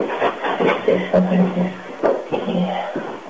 This EVP was recorded in the abandoned Supervisor's Duplex on Cumberland Island, GA, where FPR did a week long investigation in the fall of 2003. It says "There's something here. Yeah" We think the voice is male.